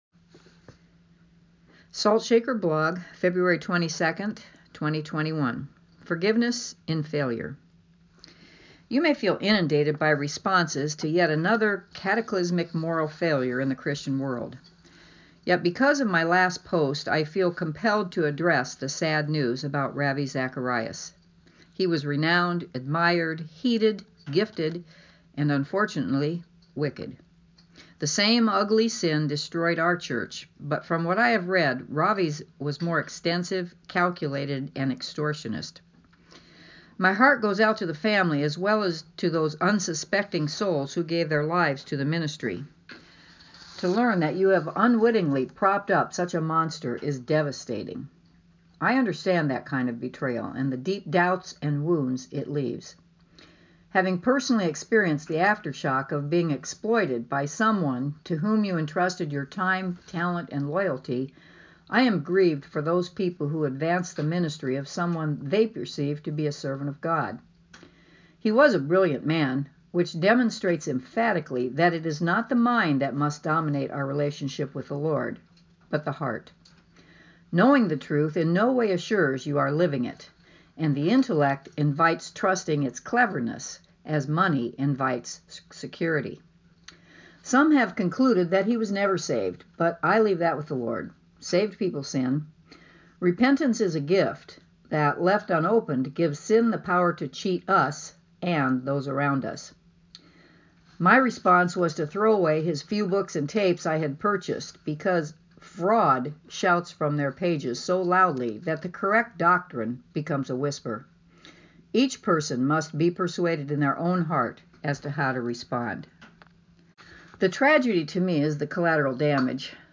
Audio version read by me: